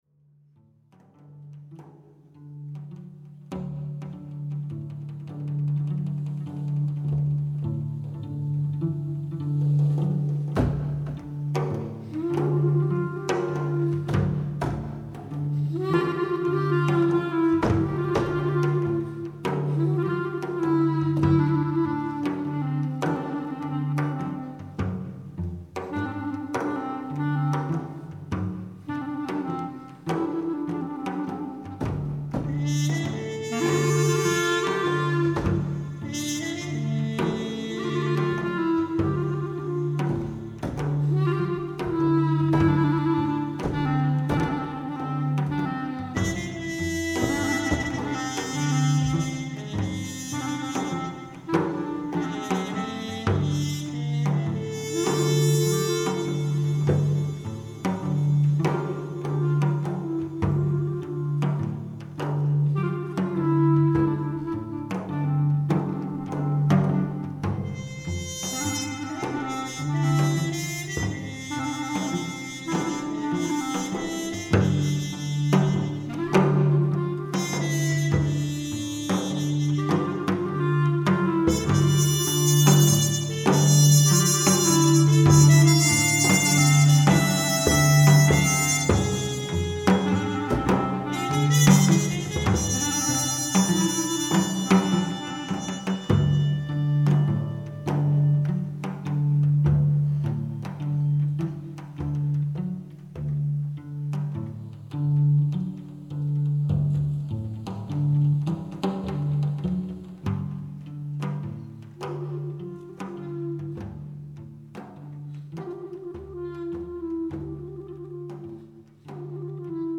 Konzertmitschnitte
Violine
Violoncello